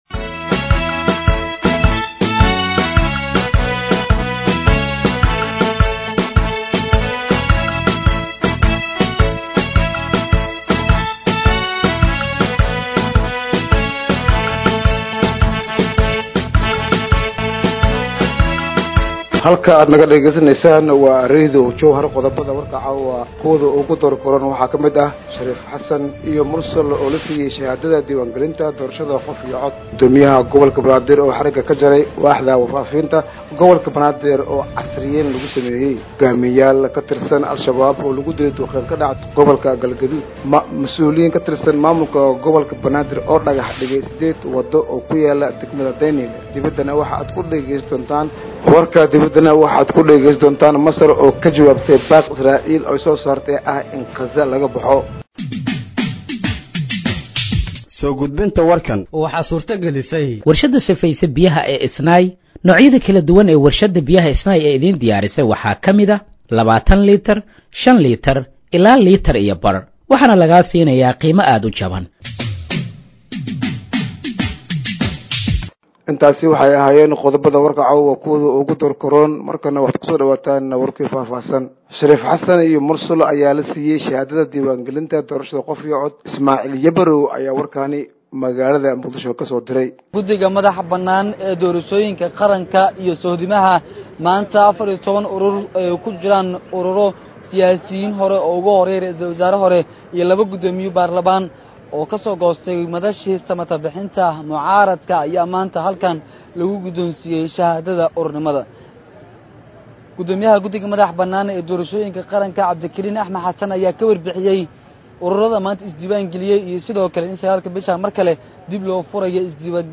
Dhageeyso Warka Habeenimo ee Radiojowhar 06/09/2025
Halkaan Hoose ka Dhageeyso Warka Habeenimo ee Radiojowhar